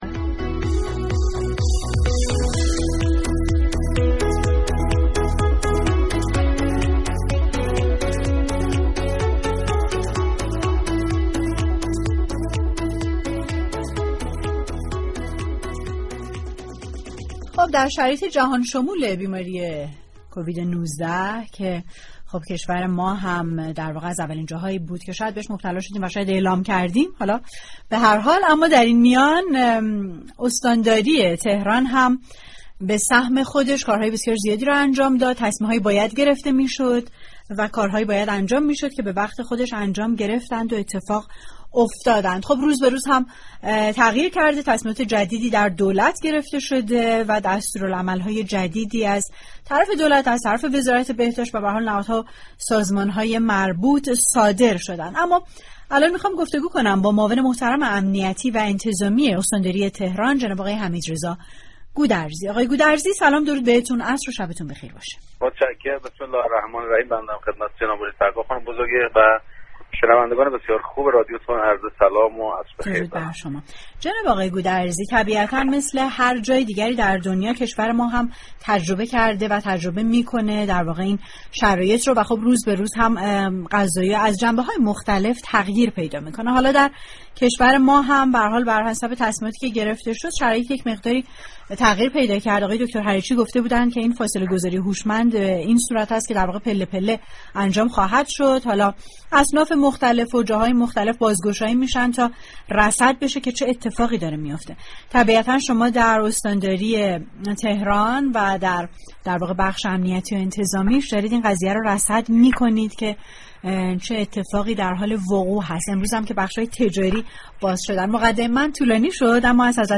حمیدرضا گودرزی در گفتگو با تهران من در سومین روز از بازگشایی اصناف و كسبه‌ی استان تهران تاكید كرد: در تهران ستاد كرونای استان و فرماندهی كرونای شهر تهران را داریم.